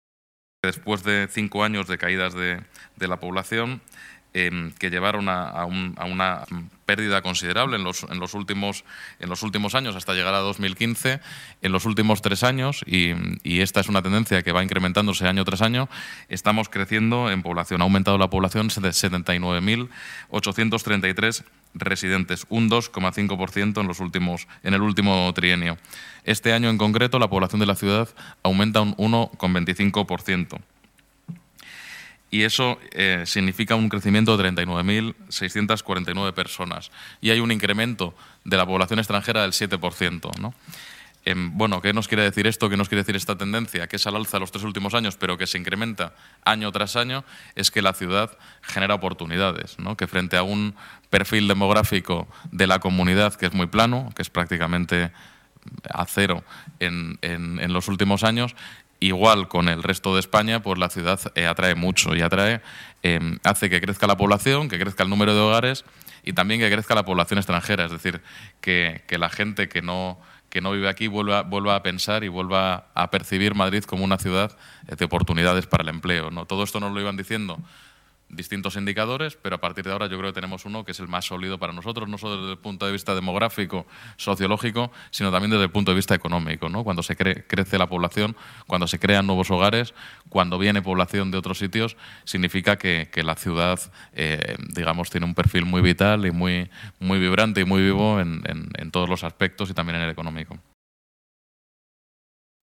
Más archivos multimedia Jorge García Castaño señala algunos datos destacables de la evolución de la población de la ciudad de Madrid en el último año Más documentos Datos que amplían la noticia sobre el Padrón Municipal